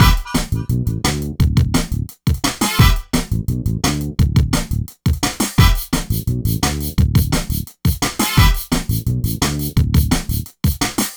13 Breakdance-d.wav